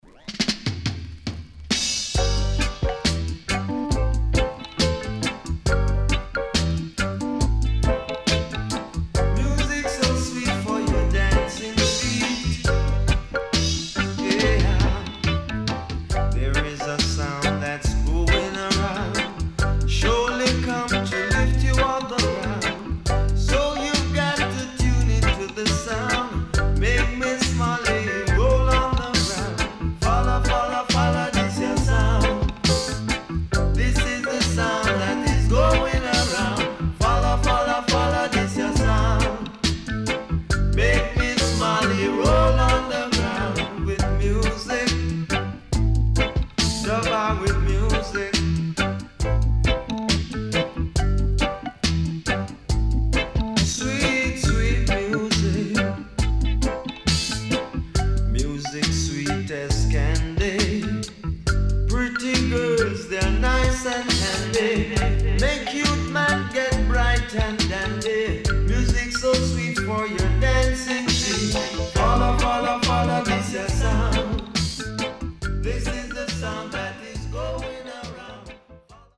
HOME > Back Order [DANCEHALL DISCO45]